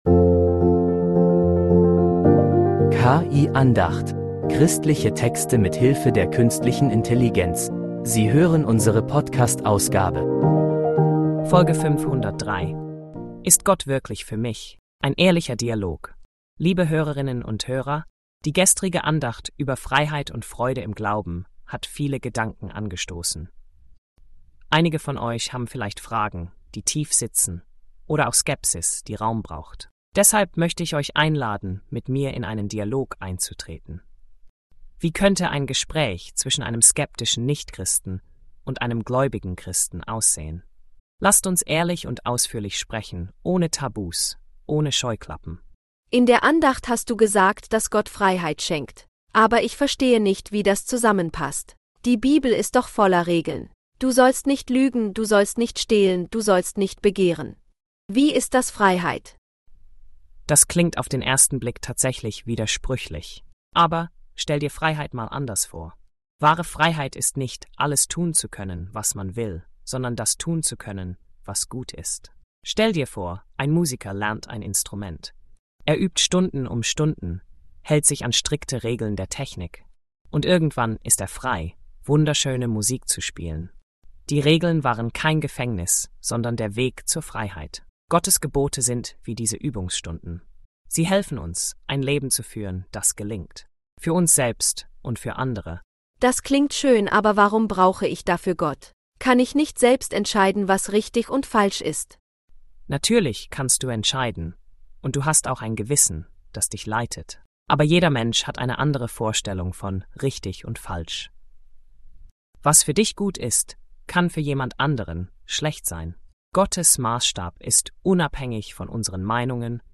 Christliche Texte mit Hilfe der Künstlichen Intelligenz
einen ehrlichen Dialog zwischen einer Skeptikerin und einem
Christen – voller Fragen, Zweifel und echter Antworten.